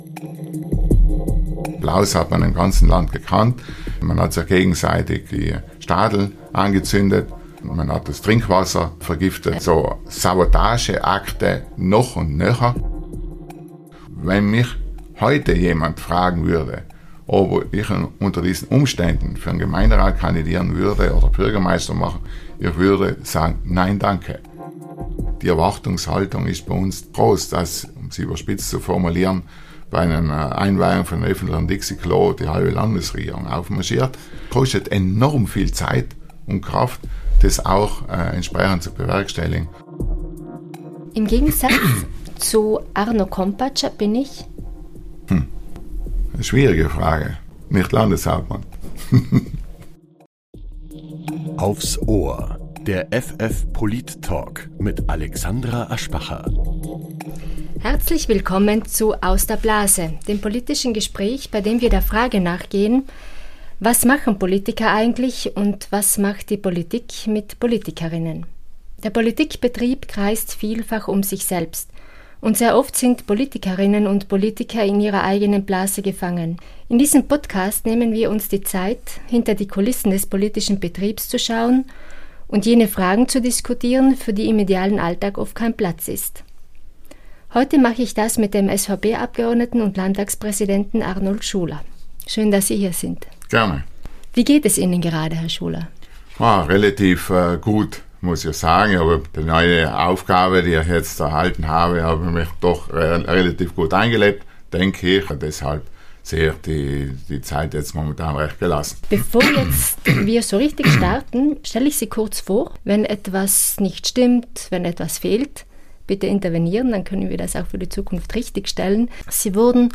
Der Politik-Talk
Gast in Folge 4 ist der Landtagspräsident Arnold Schuler